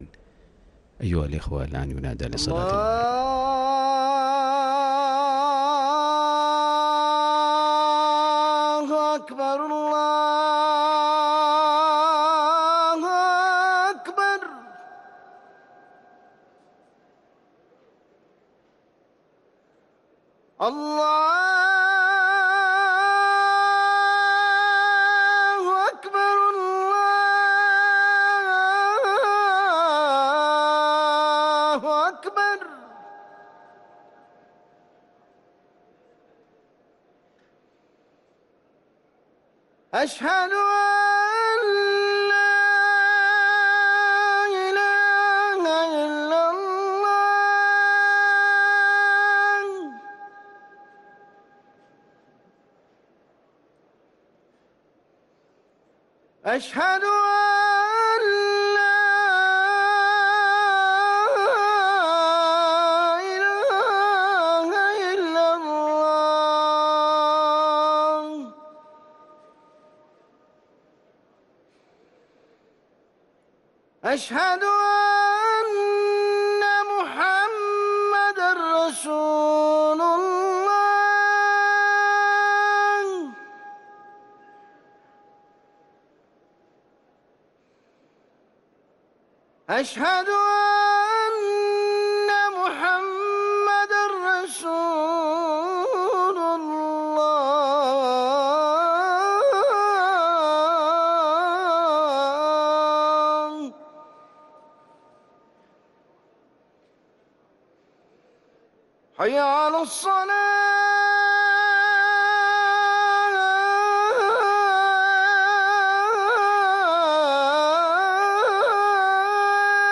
أذان المغرب